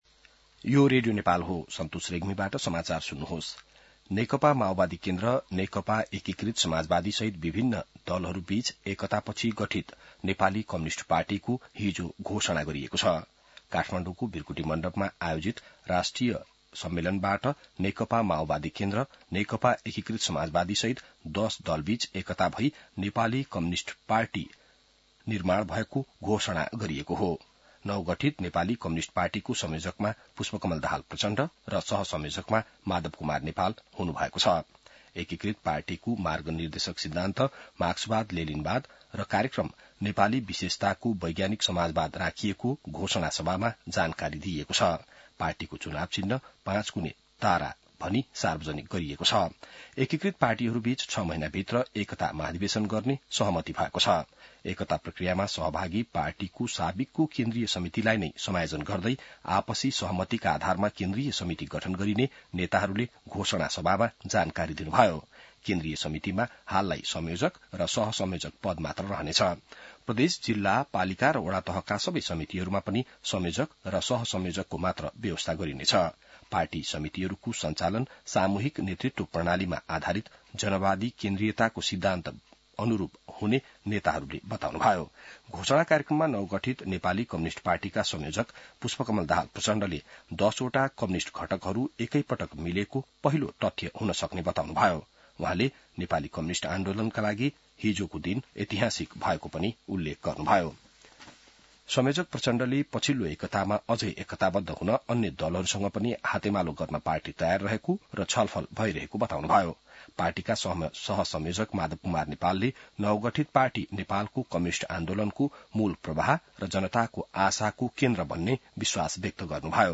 बिहान ६ बजेको नेपाली समाचार : २० कार्तिक , २०८२